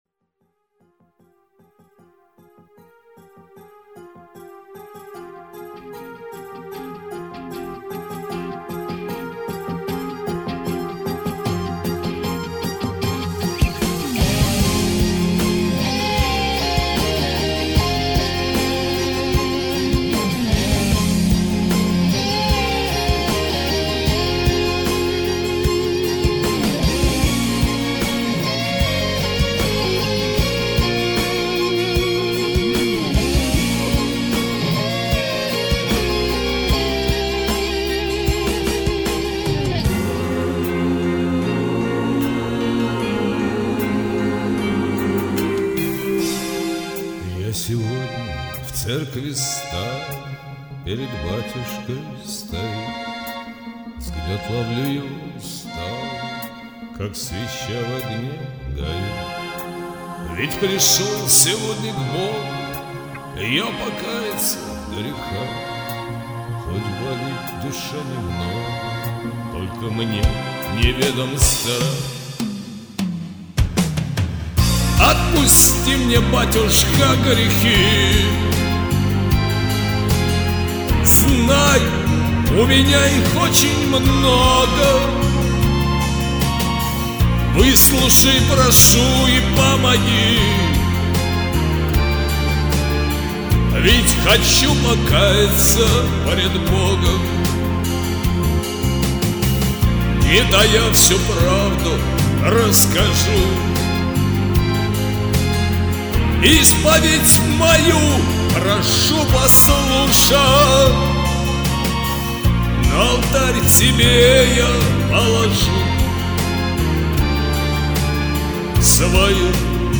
РЕВЕР ПОРТИТ КАРТИНУ...................((((((((((((((